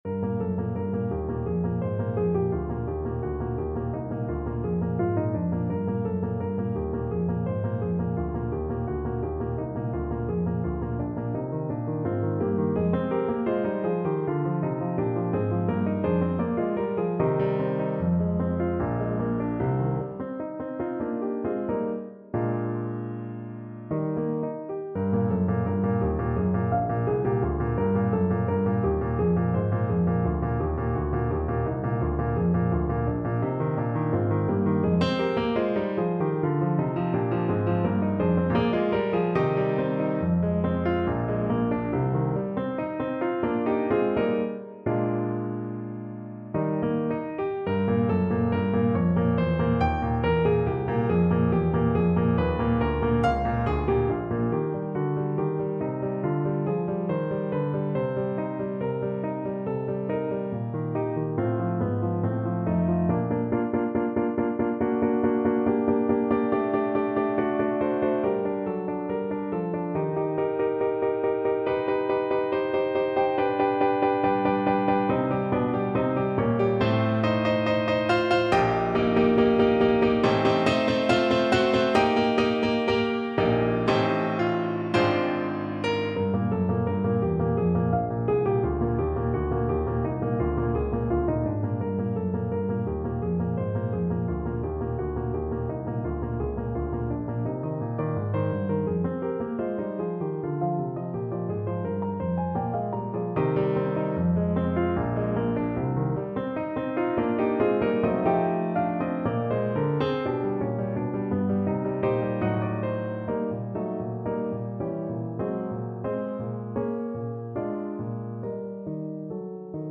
4/4 (View more 4/4 Music)
Allegretto = 85 Allegretto
Classical (View more Classical Voice Music)